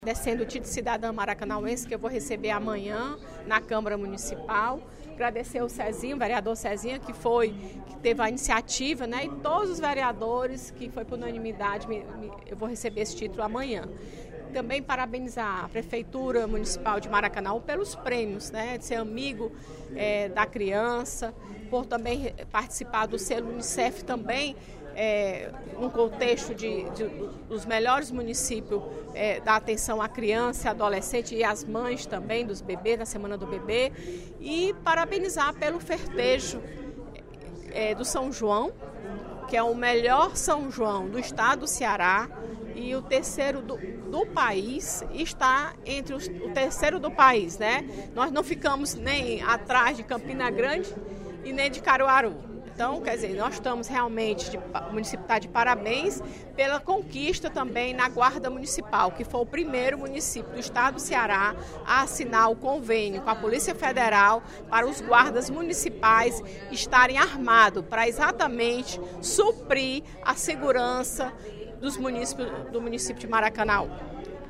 A deputada Fernanda Pessoa (PR) comunicou, durante o primeiro expediente da sessão plenária desta terça-feira (07/06), que vai ser agraciada com o Título de Cidadã Maracanauense, em evento na Câmara Municipal de Maracanaú, nesta quarta-feira (08/06), às 9h.